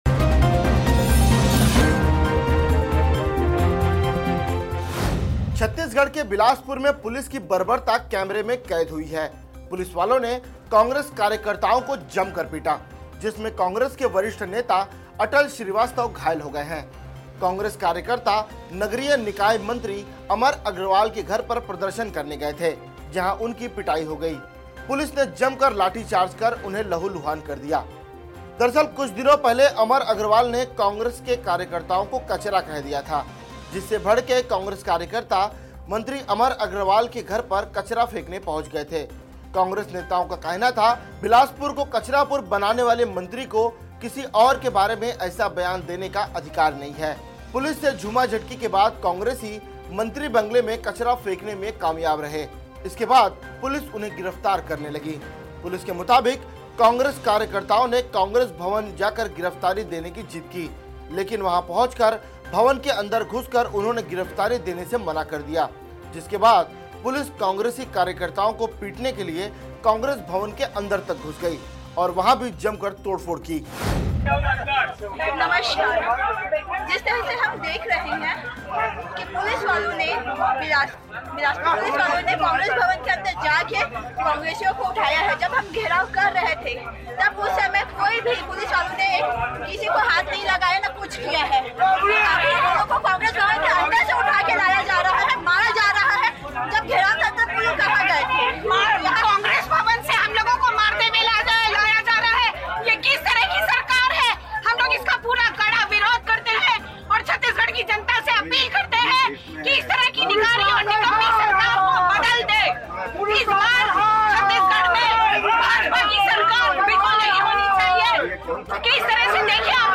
न्यूज़ रिपोर्ट - News Report Hindi / बिलासपुर में मंत्री के घर कचरा फेंकने गए कांग्रेसी कार्यकर्ताओं पर लाठीचार्ज